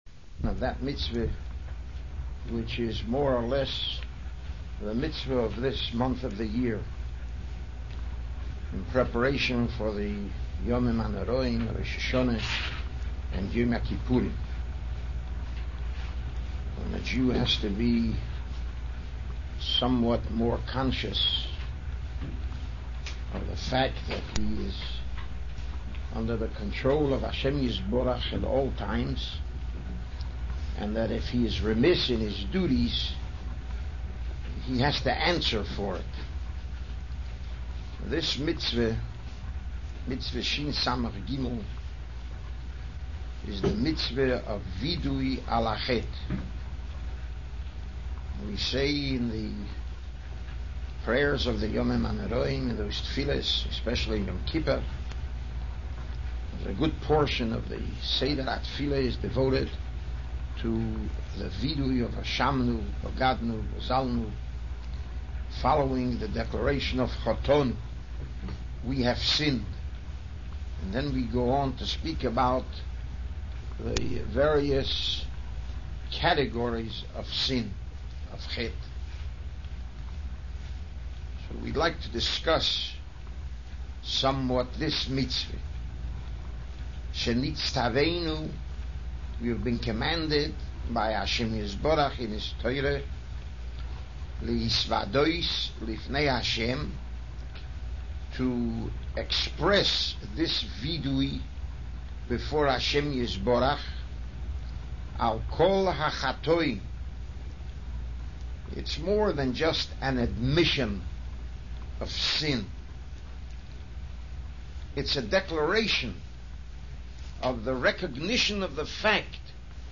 Rav Gifter giving a shiur on Minchas Chinuch – Mitzvah 363 Vidduy – part I.